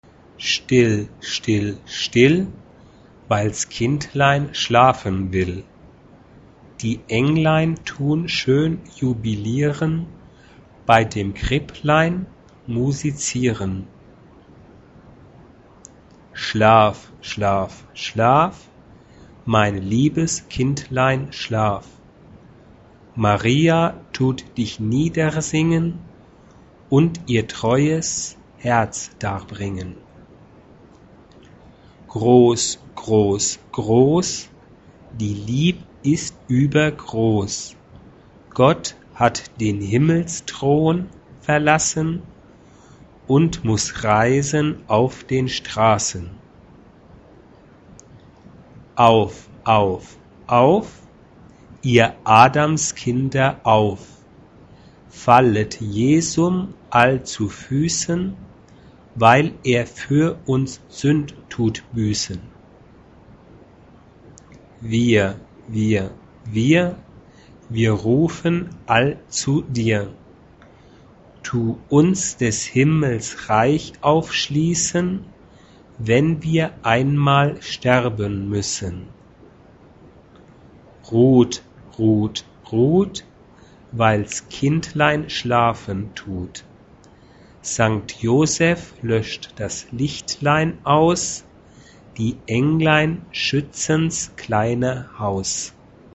SA O TB O 2-part mixed (2 voces Coro femenino O Coro de hombres O Coro mixto) ; Partitura general.
Canción de Navidad.
Género/Estilo/Forma: Sagrado ; Canción de Navidad Carácter de la pieza : calma
Instrumentos: Piano (1) ; Flauta (1)
Tonalidad : do mayor